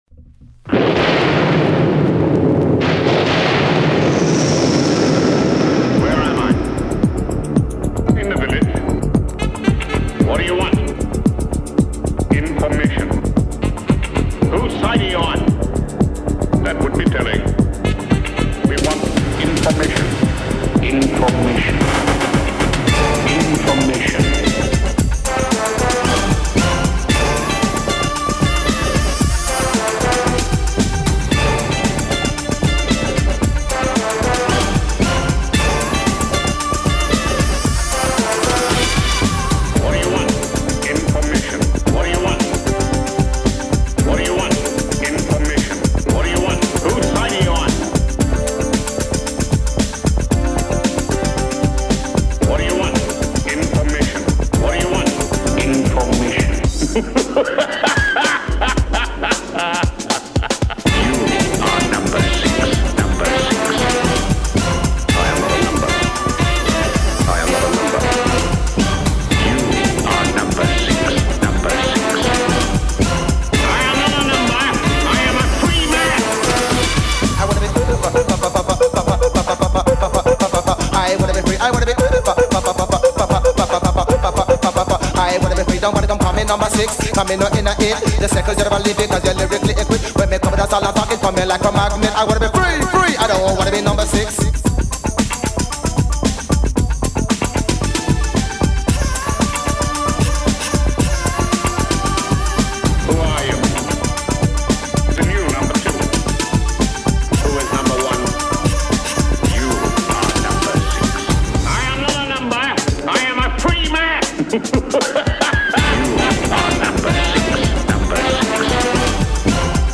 So, we will continue with revised recordings for 1970 (minor edits, mainly for sound levels).